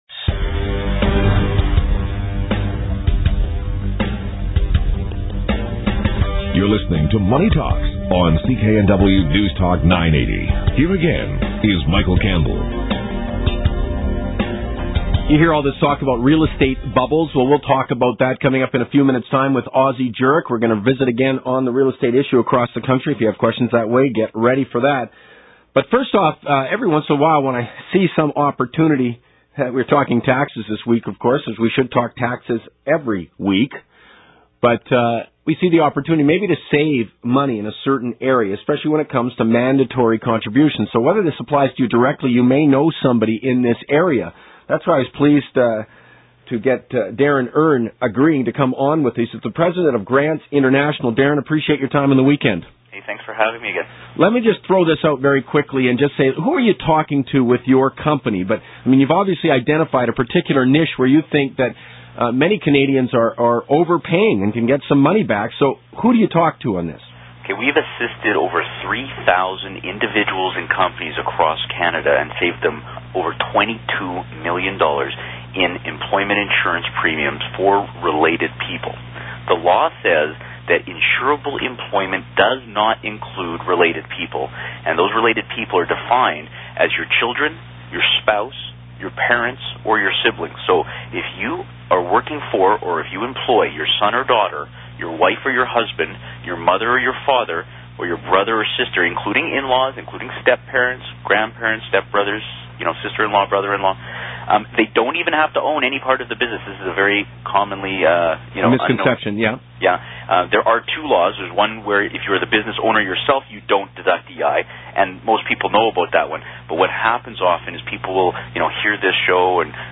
Radio Shows